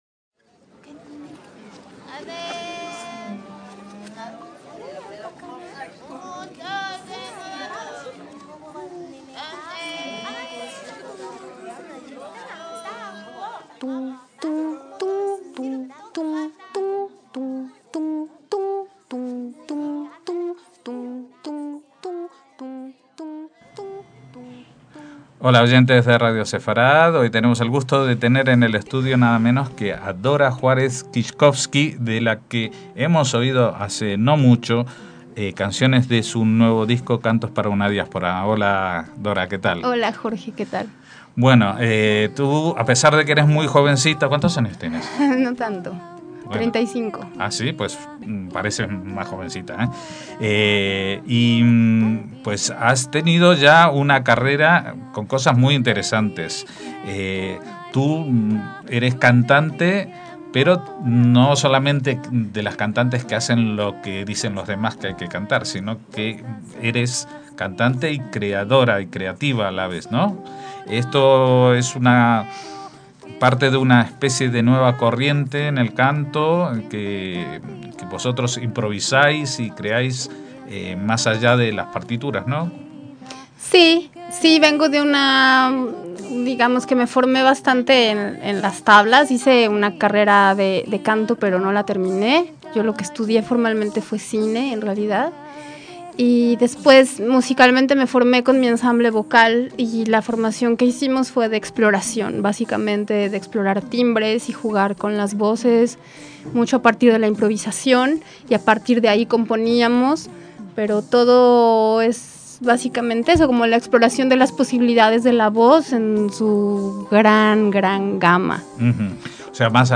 Tuvimos la oportunidad de entrevistarla en Madrid en 2013, poco después de la edición de uno de sus trabajos en el prestigioso sello Tzadik.